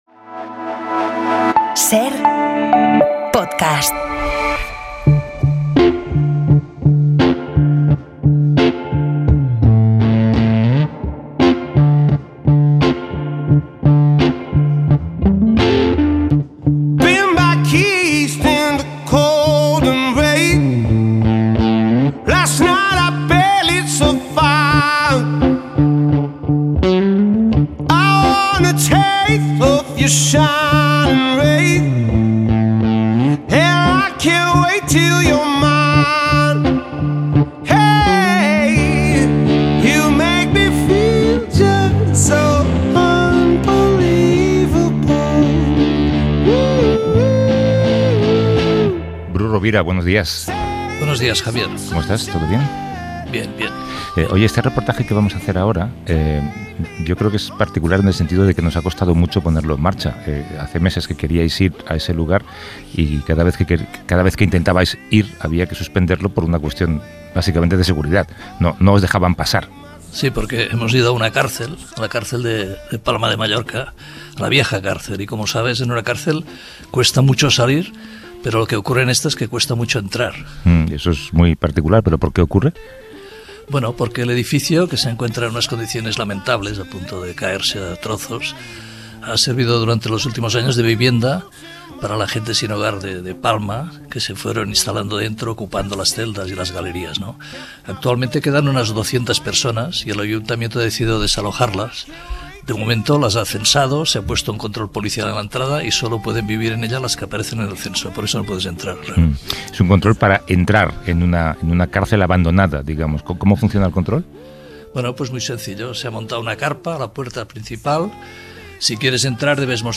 visitan la antigua cárcel de Palma (Mallorca) para conversar con algunas de las 200 personas sin hogar que malviven en lo que fuera el centro penitenciario de la ciudad. Por motivos de seguridad, el Ayuntamiento ha emitido una orden de desalojo, mientras los afectados reclaman una alternativa habitacional.